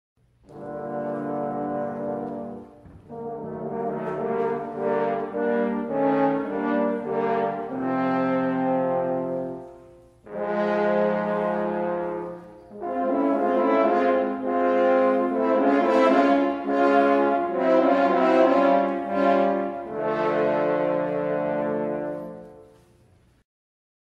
• Simfoniskā pasaka "Pēterītis un vilks" (SR) Saklausa mūzikas instrumentiem atbilstošus tēlus